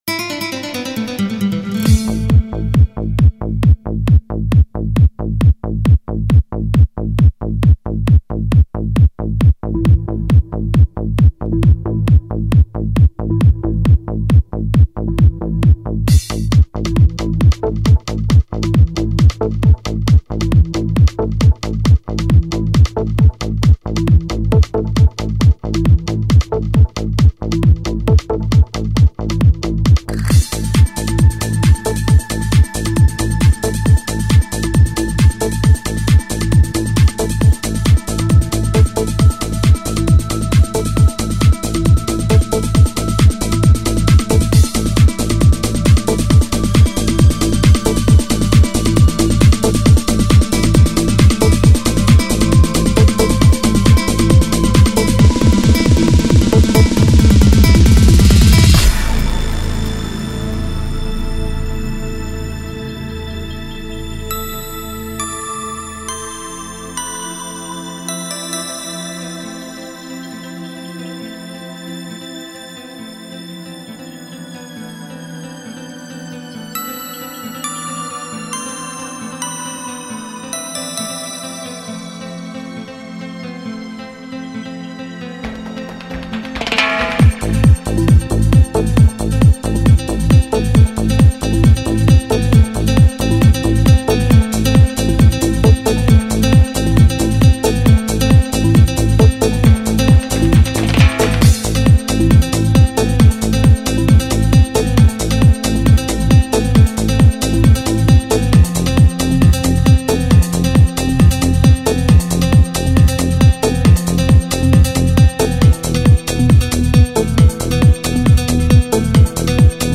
Энергия - эмоции - чувства - движение...
Жанр:Trance